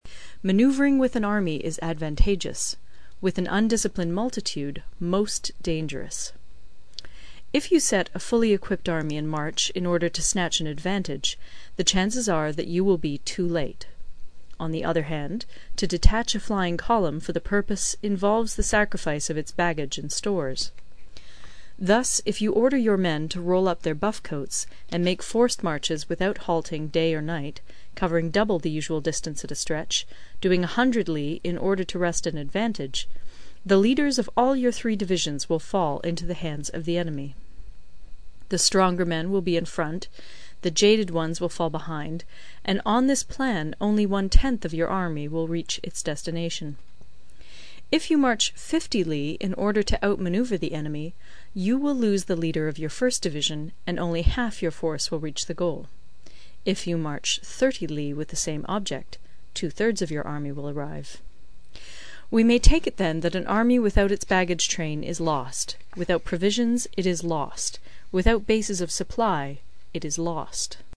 有声读物《孙子兵法》第40期:第七章 军争(2) 听力文件下载—在线英语听力室